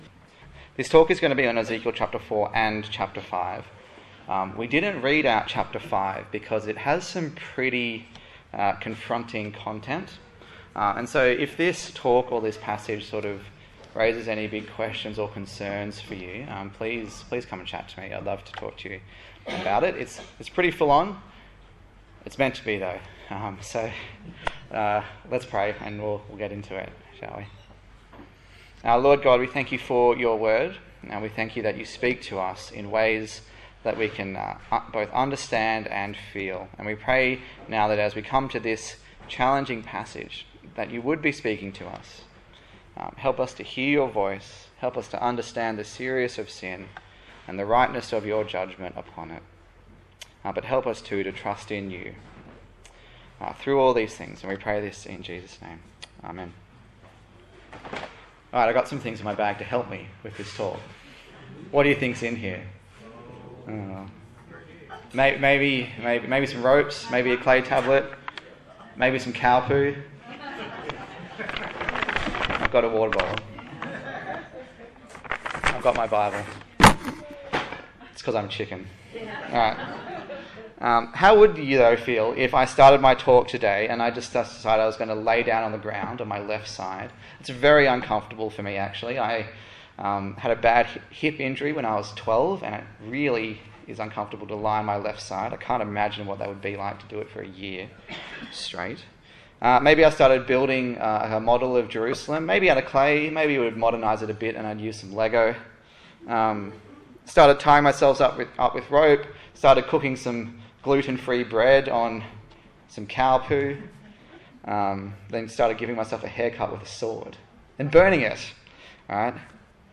Ezekiel Passage: Ezekiel 4, Ezekiel 5 Service Type: Sunday Morning A sermon in the series on the book of Ezekiel